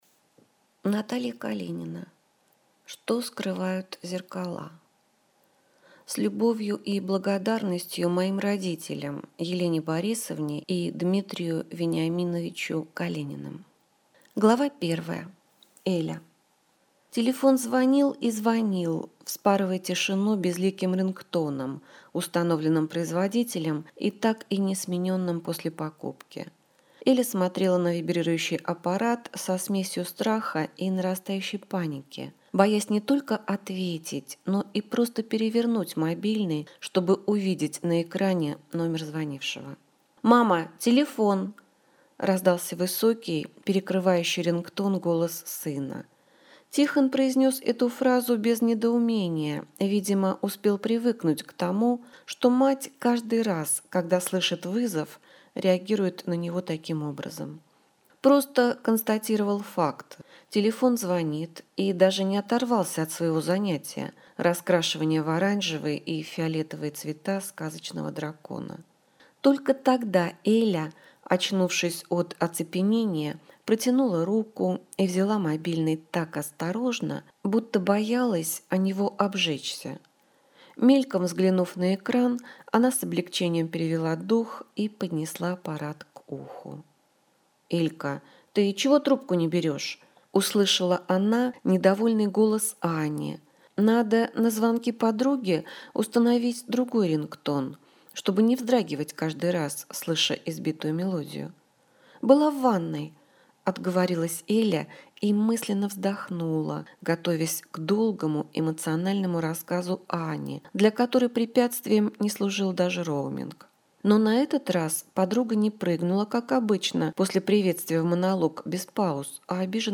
Аудиокнига Что скрывают зеркала | Библиотека аудиокниг